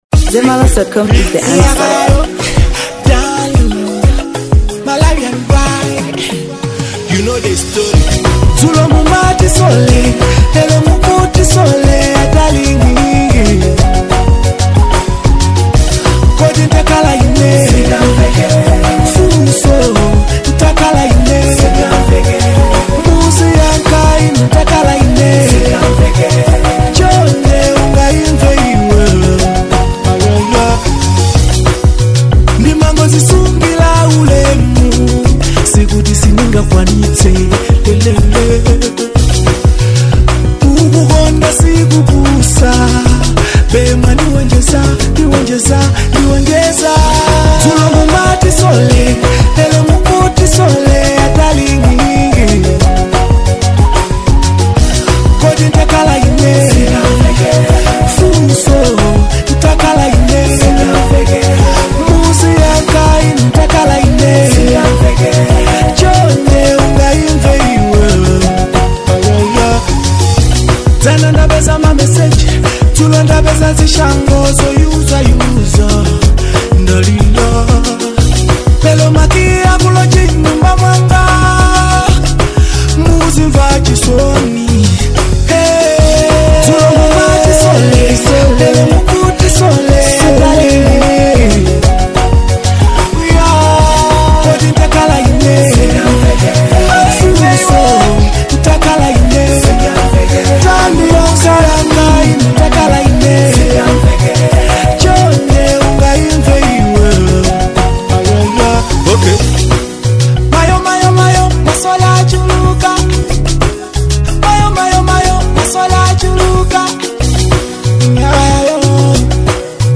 Afrobeats • 2025-08-04